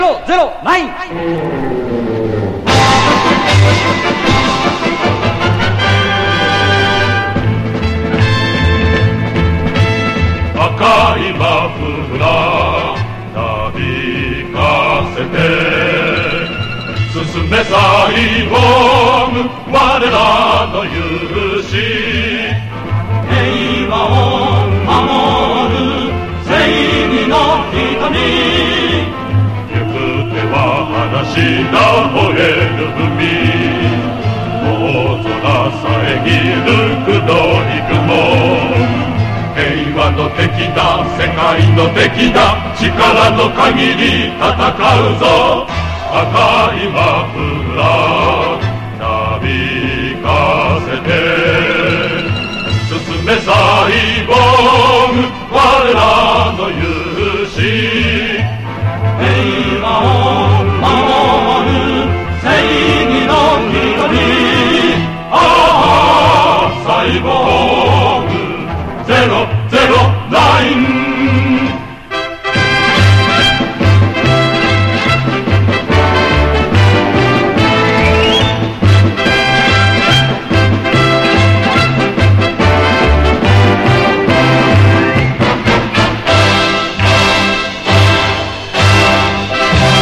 ビッグバンドによるオケを使ったいかにも昭和アニメな楽曲です。
POP